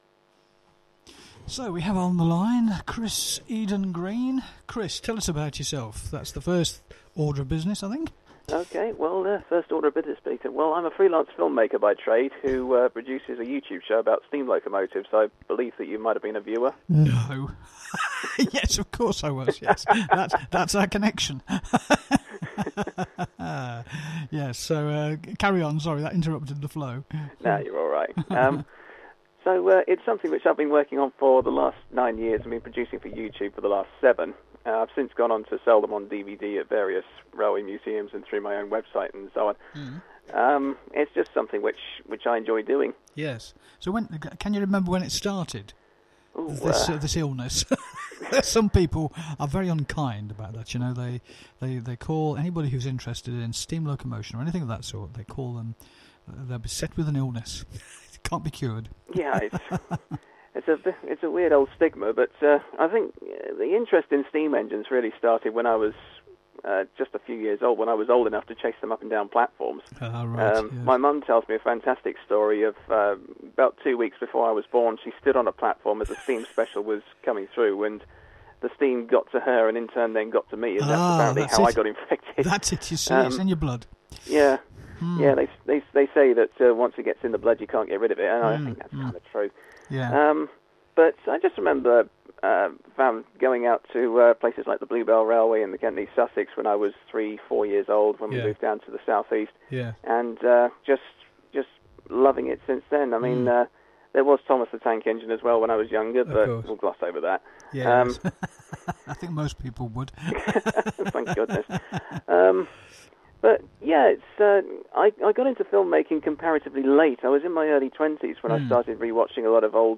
Listen to the conversation… and then order one of his SLIPs!!